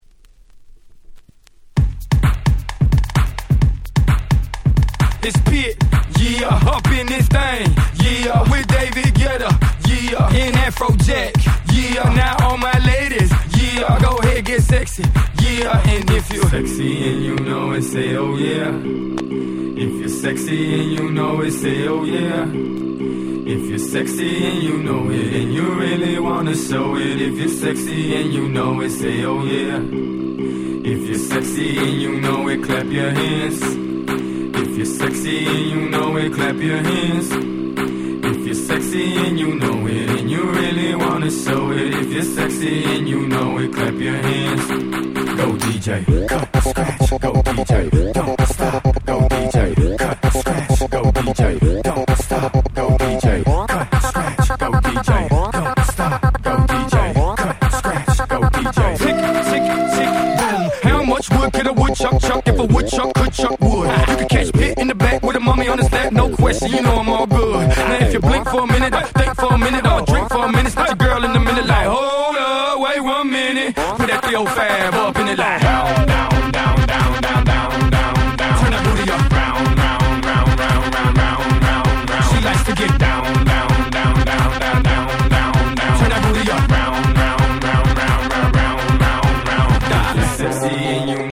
11' Super Hit EDM / R&B / Hip Hop !!